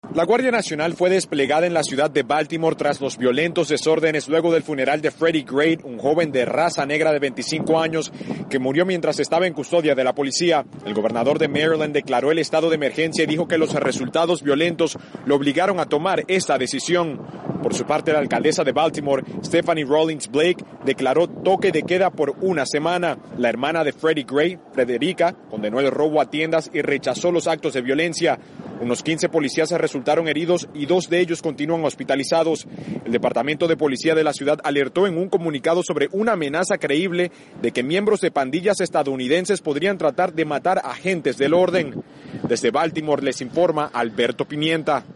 VOA: BALTIMORE Despacho reciente